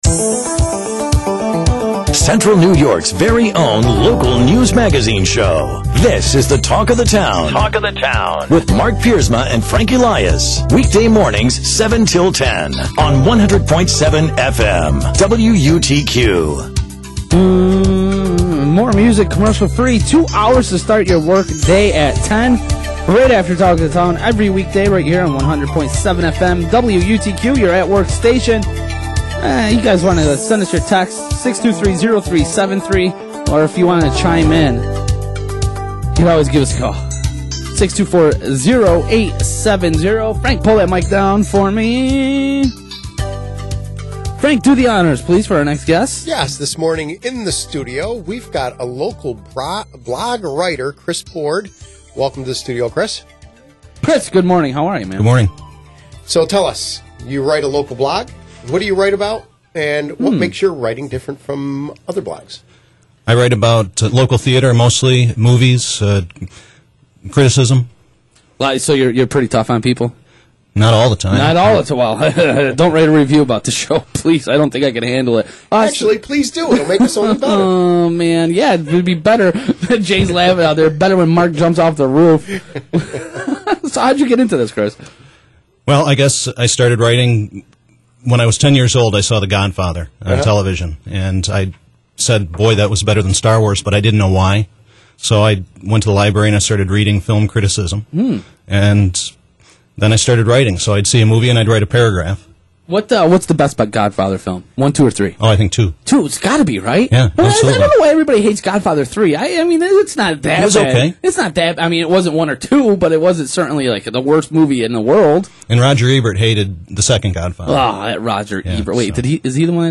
Talk of the Town radio show